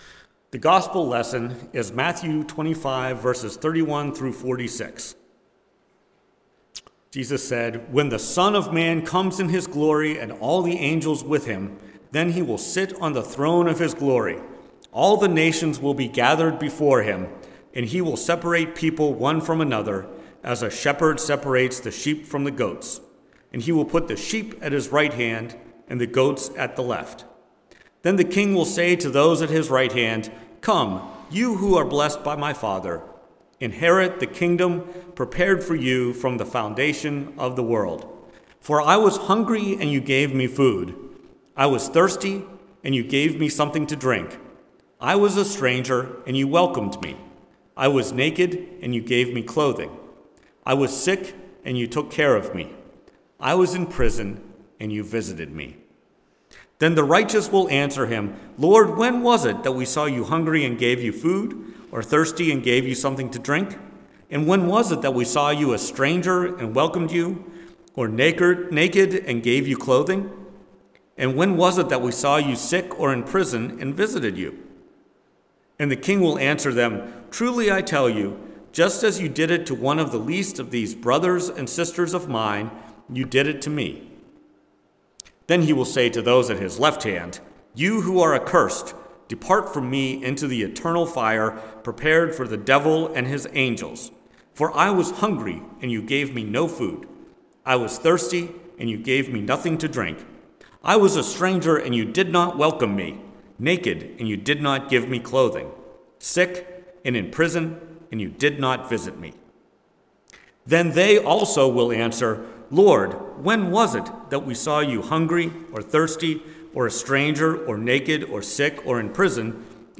Preached at First Presbyterian Church of Rolla on November 26, 2023, Reign of Christ Sunday. Based on Matthew 25:31-46.